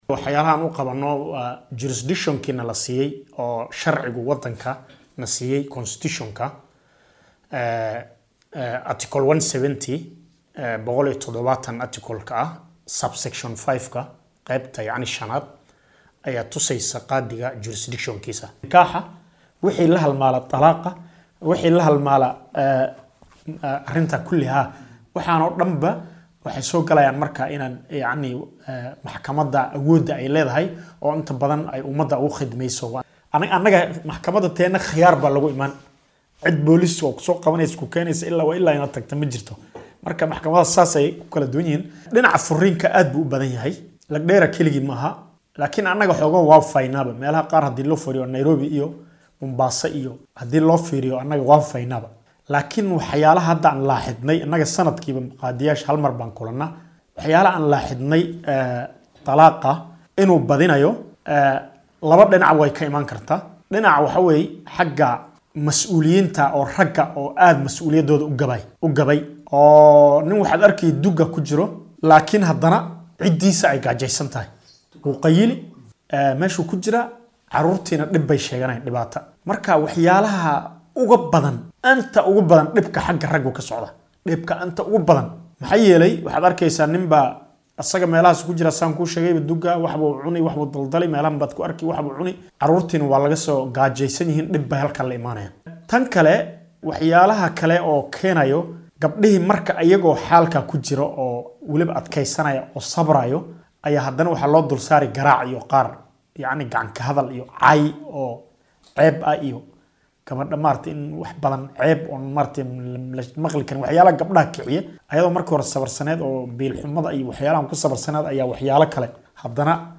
Qaadiga deegaan baarlamaneedka Lagdera ee ismaamulka Garissa Sheekh Cabdicasiis Macallin Maxamad oo wareysi gaar ah siiyay warbaahinta Star ayaa sheegay in halkaasi uu ku badan yahay burburka qoyska isagoo sidoo kale faahfaahin ka bixiyay shaqooyinka uu xafiiskiisa oo ku yaalla magaalada Madogashe uu u hayo bulshada.